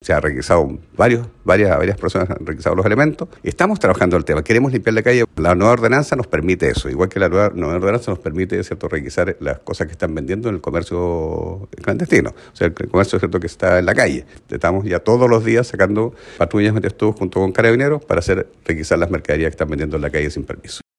El alcalde de Osorno, Jaime Bertin, indicó que con la nueva ordenanza ha permitido requisar los productos que se venden sin autorización, destacando que con ello “queremos limpiar las calles”.